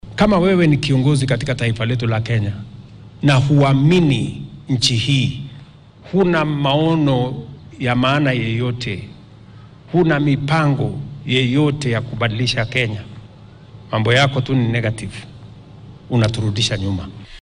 Waxaa uu munaasabad kaniiseed oo uu uga qayb galay degmada Maralal ee ismaamulka Samburu ka sheegay in madaxda laga doonaya in ay ka waantoobaan sumcad xumeynta himilooyinka horumarineed ee dowladda.